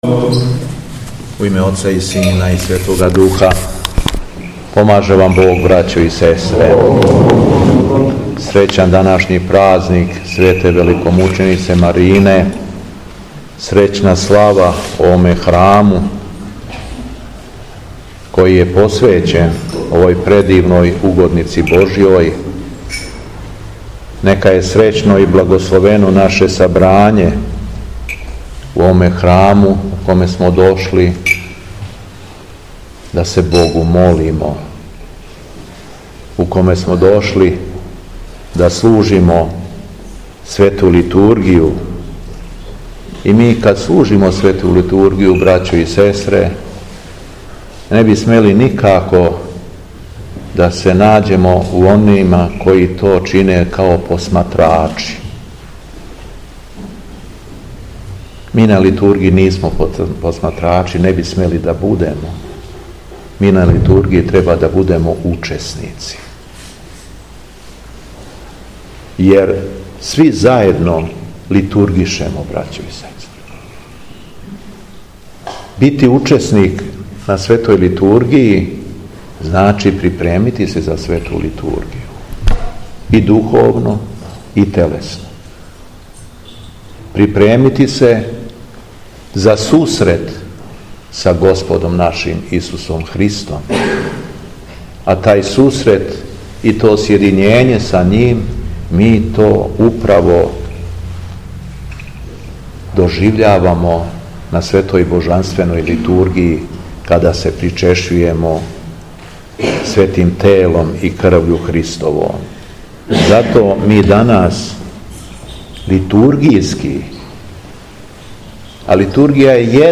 У среду, 30. јула 2025. године, када Црква прославља Свету Великомученицу Марину, Његово Високопреосвештенство Митрополит шумадијски Господин Г. Јован, служио је Свету Архијерејску Литургију у Горњој Сабанти, поводом храмовне славе, уз саслужење свештенства и свештеномонаштва шумадијске епархије.
Беседа Његовог Високопреосвештенства Митрополита шумадијског г. Јована
Епископ се верном народу обратио богонадахнутом беседом, рекавши: